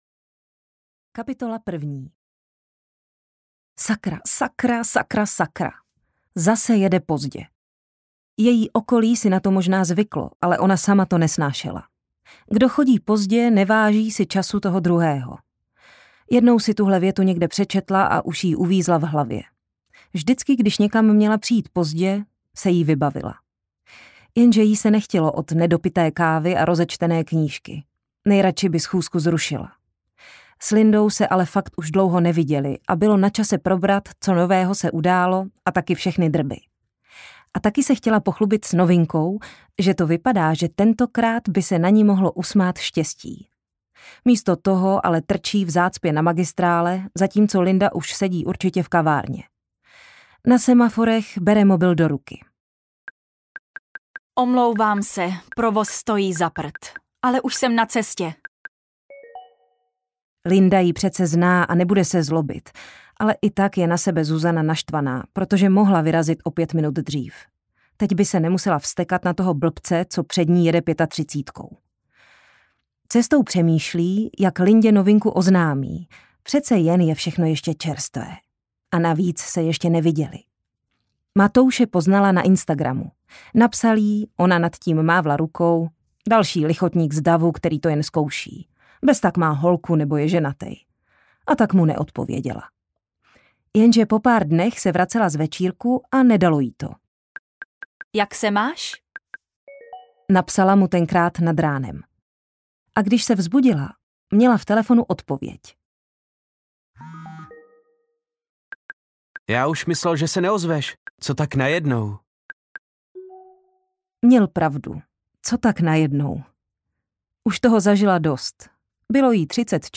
Neodpovídej audiokniha
Ukázka z knihy